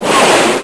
1 channel
Missile1.wav